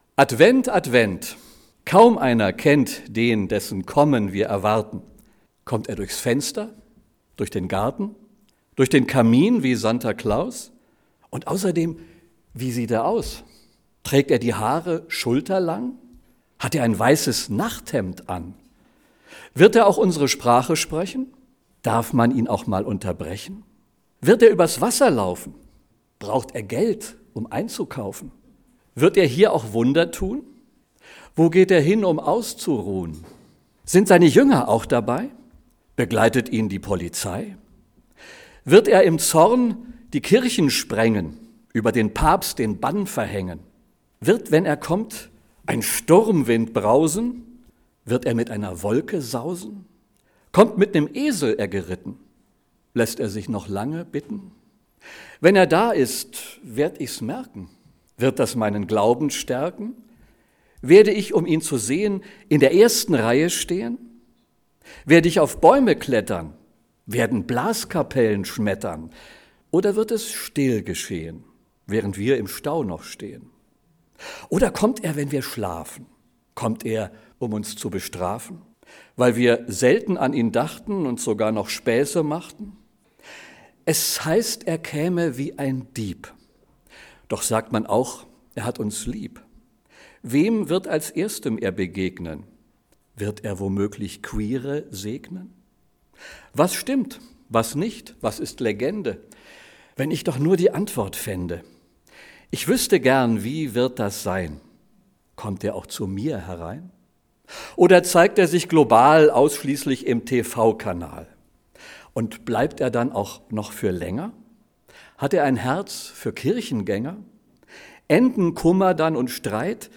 Statt Predigt ein Adventsgedicht
Nicht ganz ernst zu nehmende Überlegungen über die Frage, wie die Wiederkunft von Jesus Christus möglicherweise aussehen könnte… – Ein Beitrag während der Adventsfeier unserer Gemeinschaft.